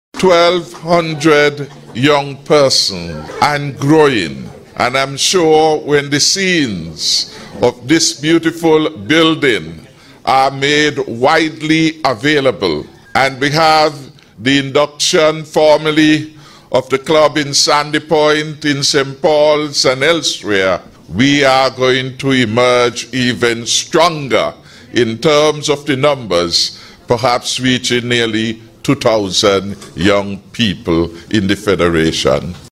During the opening ceremony of the Explorers Youth Club’s new Headquarters, Prime Minister and Minister of National Security, Dr. the Hon. Timothy Harris  expressed his high hopes that the next leaders, that will be Police Chief, Fire Chief etc., will emerge from the ever growing Explorers group.
He continued by speaking about his hopes of the future membership numbers: